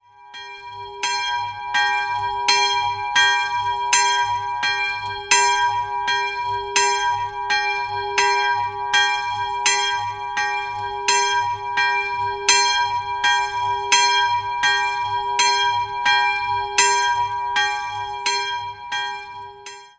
Am westlichen Ortseingang steht die kleine Kapelle zur Hl. Maria aus der Mitte des 19. Jahrhunderts mit einfacher Ausstattung. Einzelglocke gis'' (tief) Die Glocke wurde 1950 von der Gießerei Karl Hamm in Regensburg gegossen.